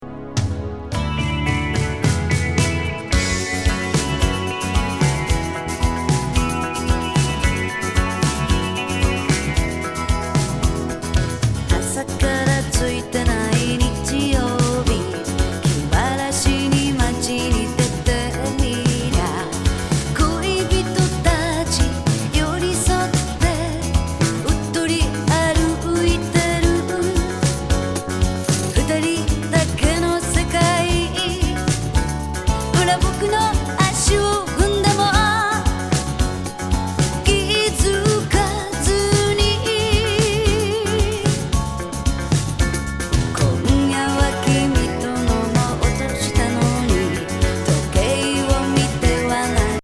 強力フォーキー・グルーブ